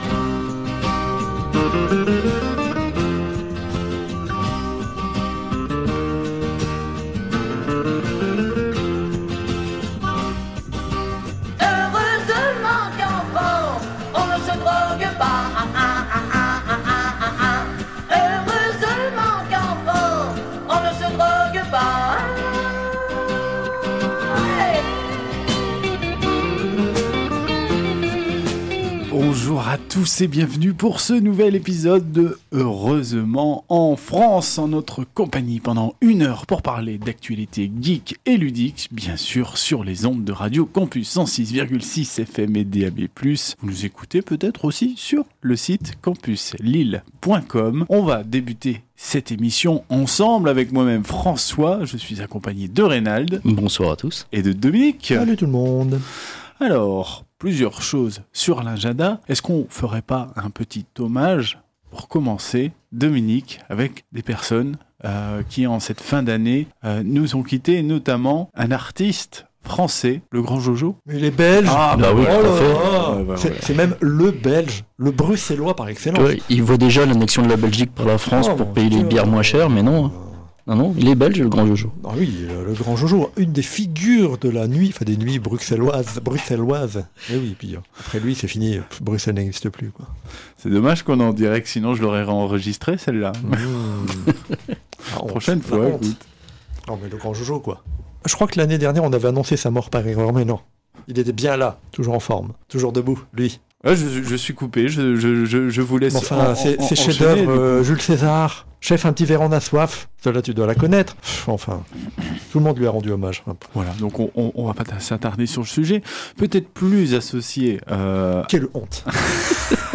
Au sommaire de cet épisode diffusé le 12 décembre 2021 sur Radio Campus 106.6 :
– l’agenda geek et ludique du moment – de la musique de saison